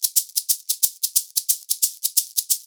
Index of /90_sSampleCDs/USB Soundscan vol.36 - Percussion Loops [AKAI] 1CD/Partition A/10-90SHAKERS